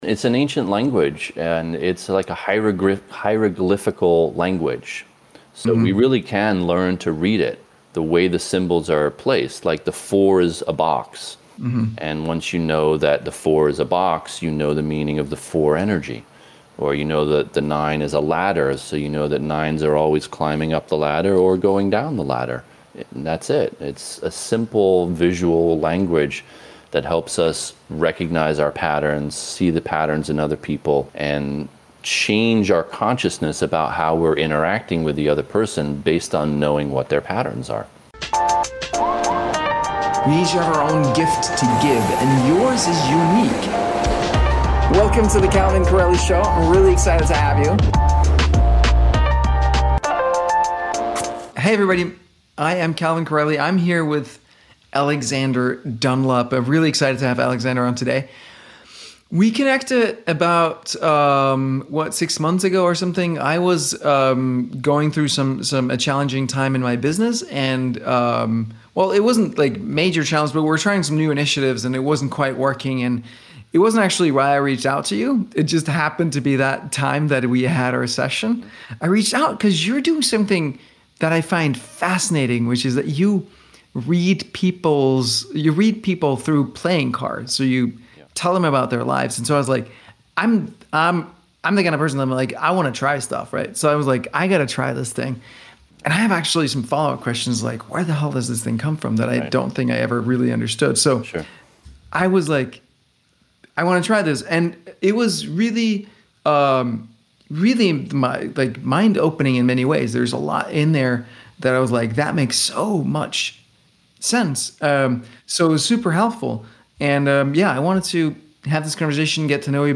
Play Your Cards Right An Interview